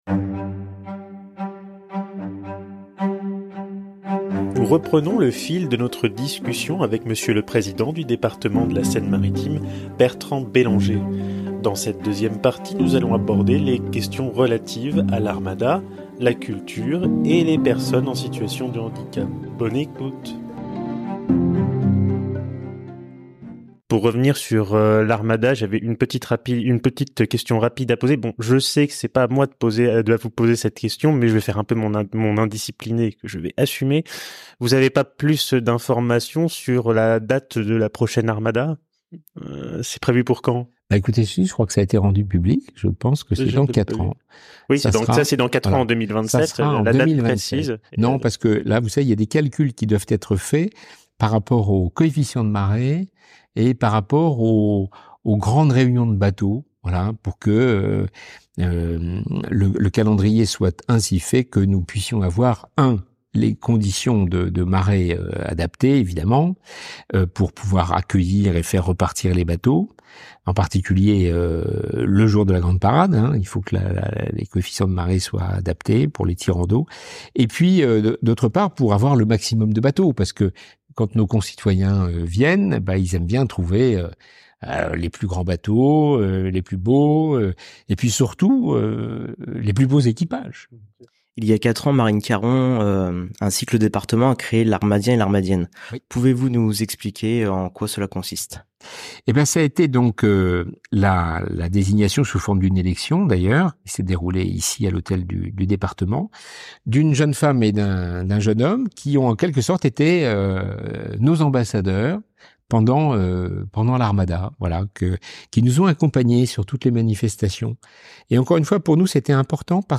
Deuxième partie de notre entretien avec Monsieur, le président du département de la Seine-Maritime.